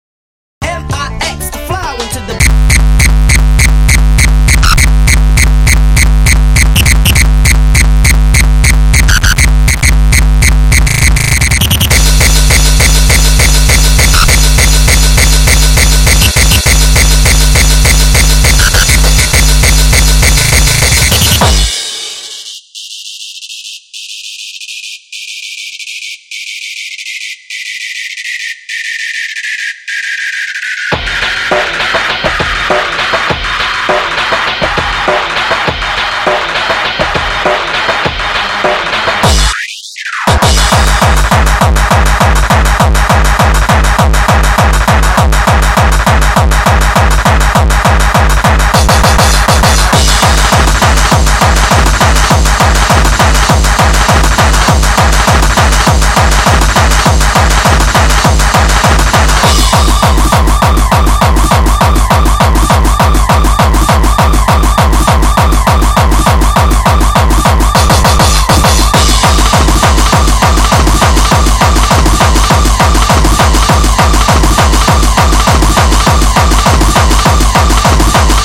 unfinished hardcore track...